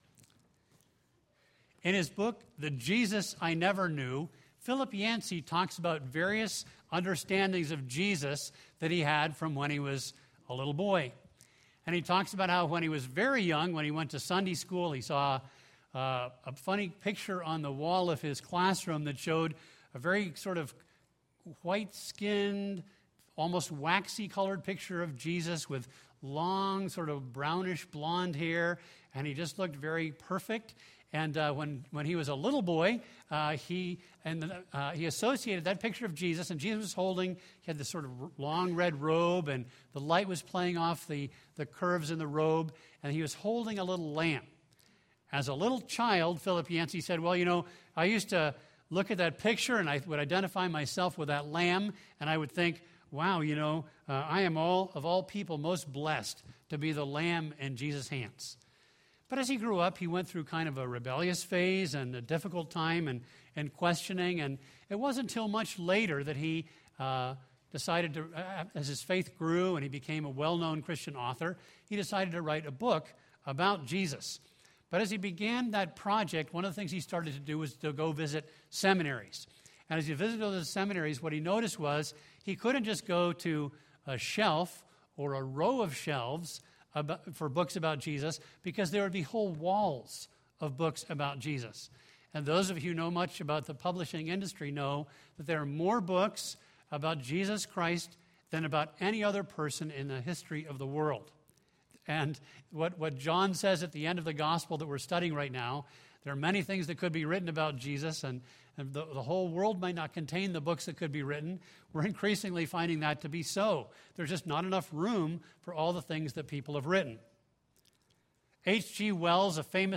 A message from the series "Gospel of John."